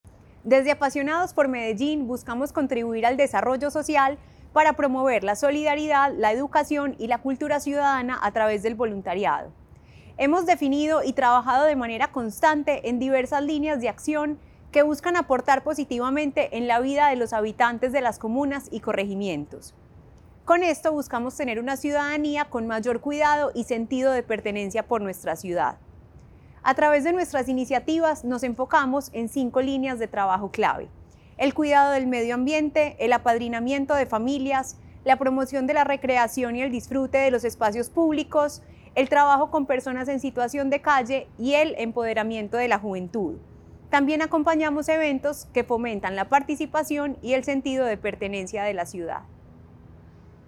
Declaraciones de la subsecretaria de Ciudadanía Cultural Natalia Londoño
Audio-Declaraciones-de-la-subsecretaria-de-Ciudadania-Cultural-Natalia-Londono.mp3